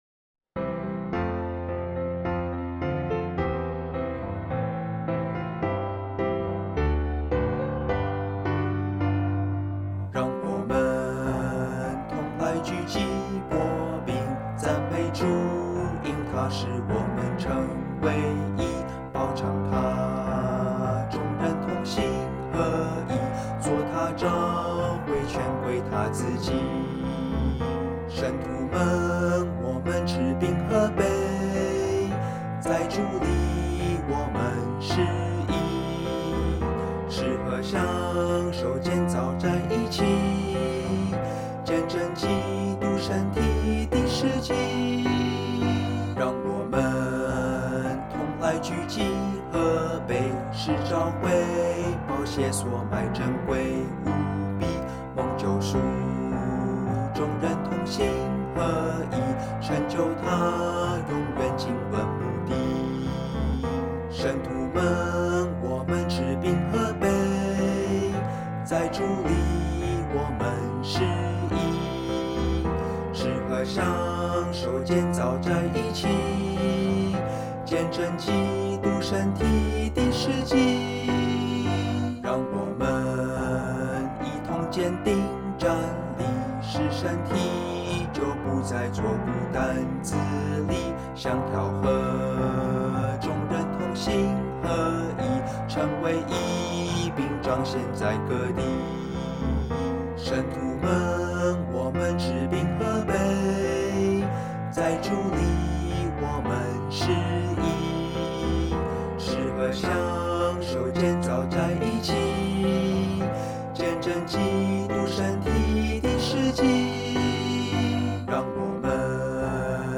第五节不唱副歌
降E大調